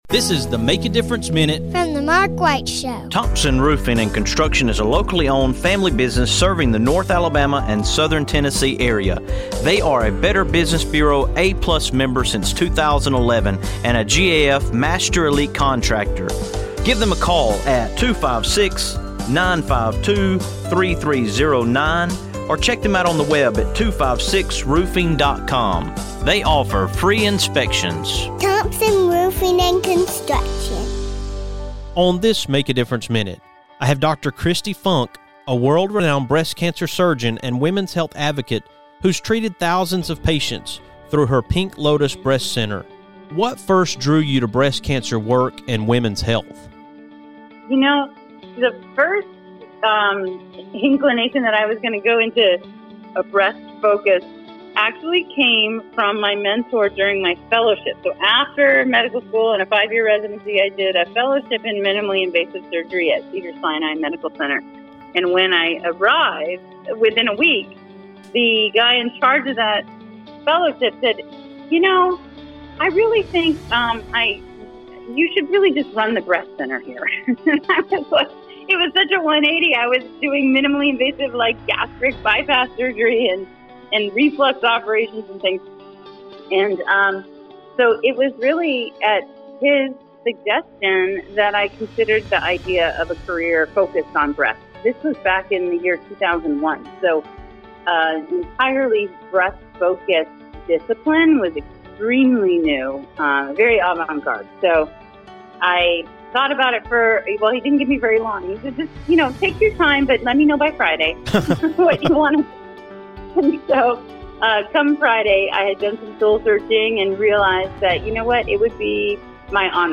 On this Make A Difference Minute, you’ll hear from Dr. Kristi Funk, world-renowned breast cancer surgeon and author of Breasts: The Owner’s Manual.